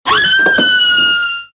GIRL SCREAM.mp3
A young girl screaming in desperation.
girl_scream_o5h.ogg